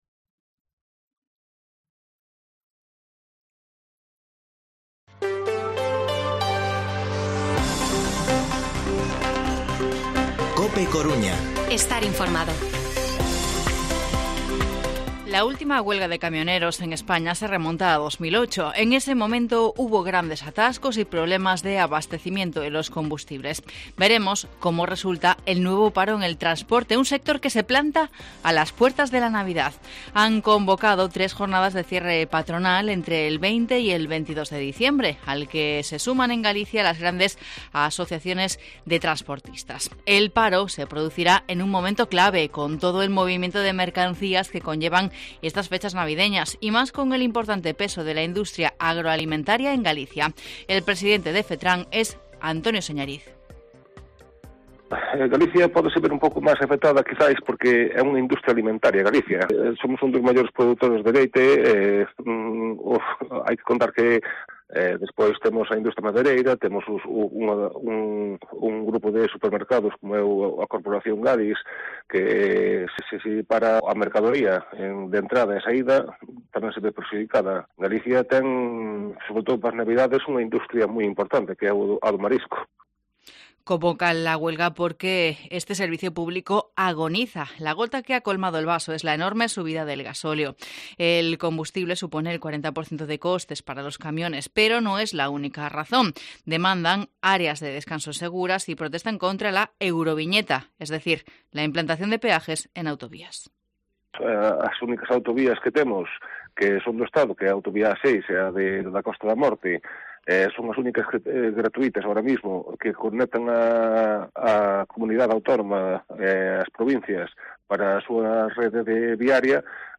Informativo Mediodía COPE Coruña jueves, 11 de noviembre de 2021 14:20-14:30